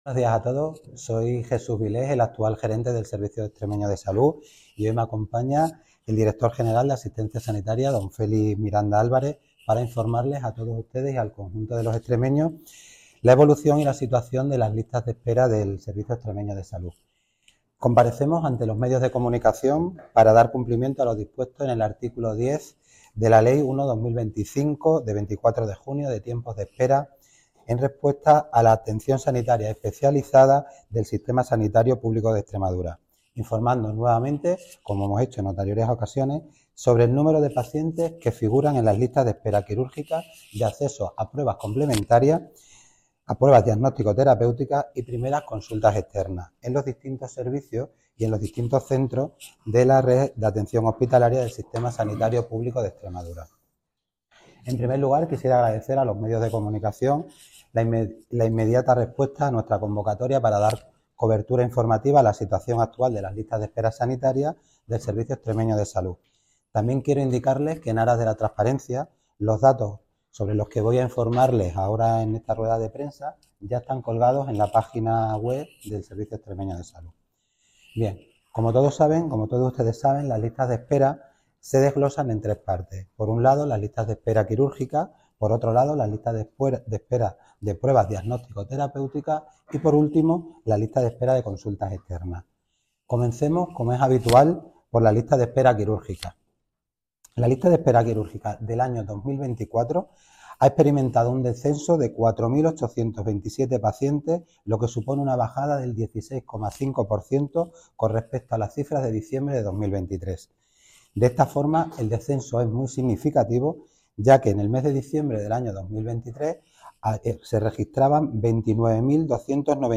Declaraciones del director gerente del Servicio Extreme�o de Salud (SES), Jes�s Vil�s .